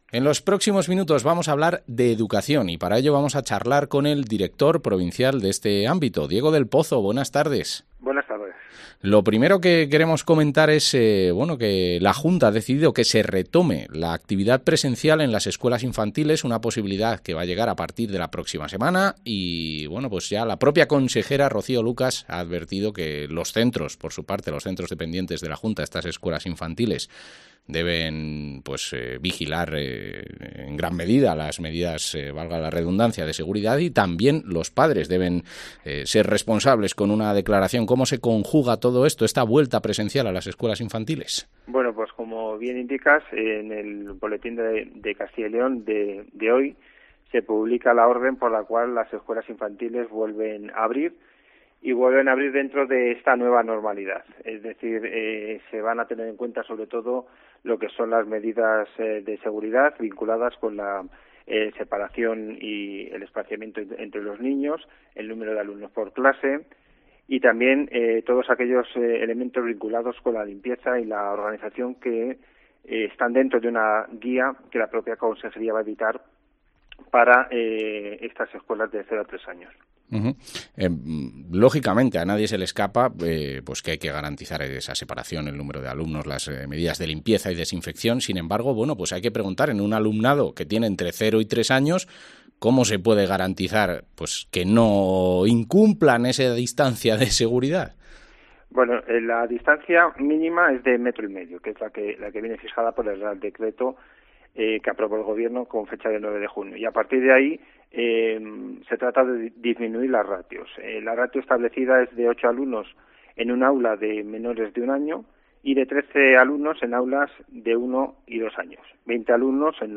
Entrevista al director provincial de Educación, Diego del Pozo